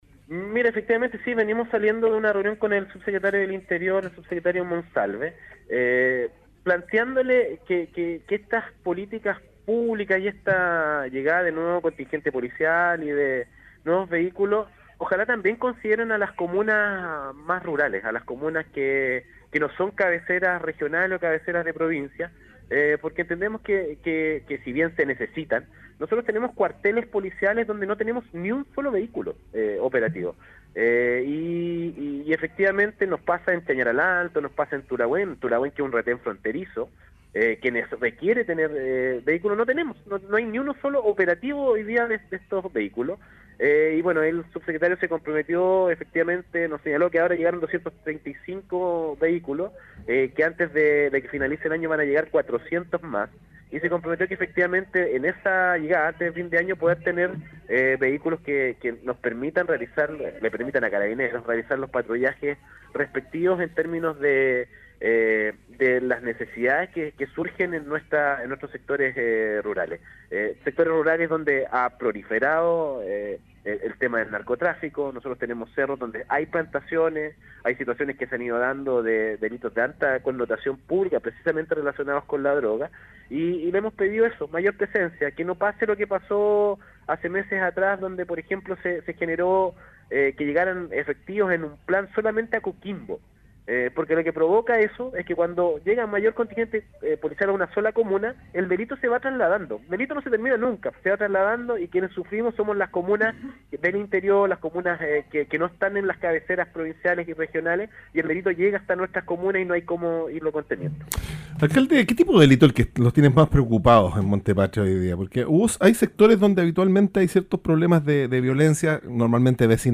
ENTREVISTA-CRISTIAN-HERRERA.mp3